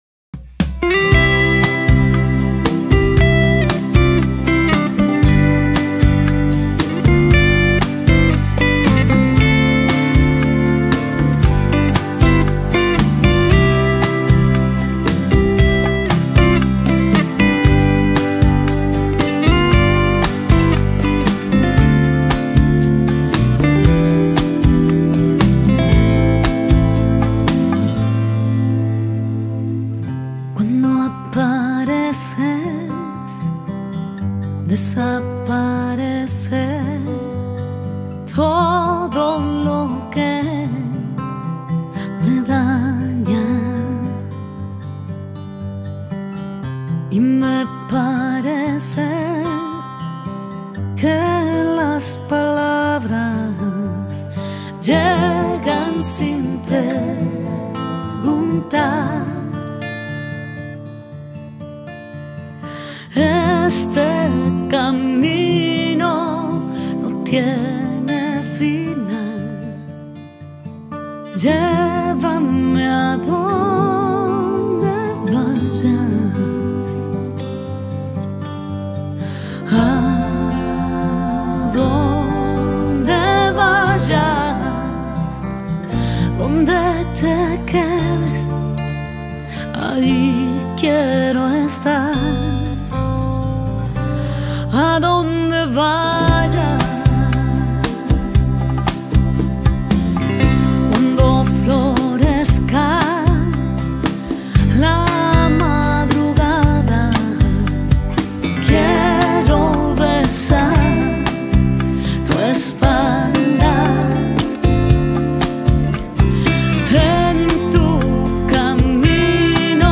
Universal Gospel
The passionate Argentine folk singer